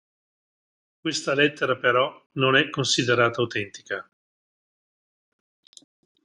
Pronounced as (IPA) /ˈlet.te.ra/